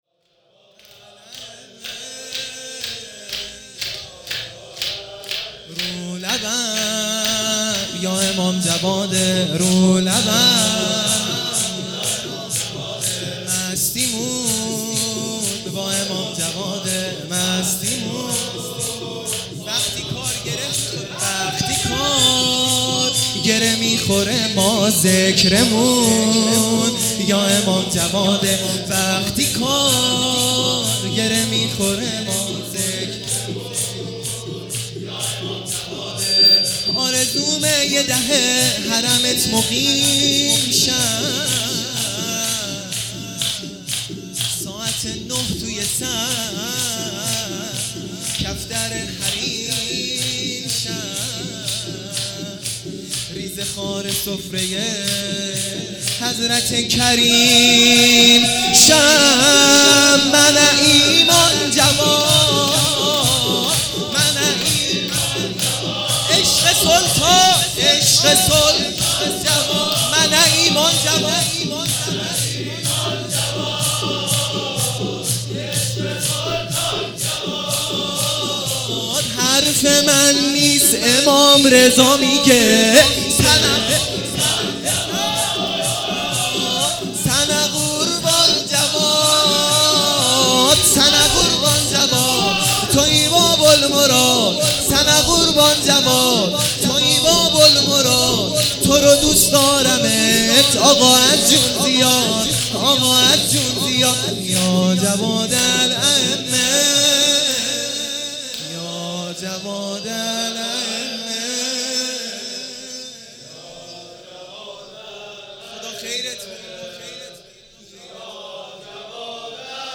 خیمه گاه - بیرق معظم محبین حضرت صاحب الزمان(عج) - شور ا سنه قوربان جواد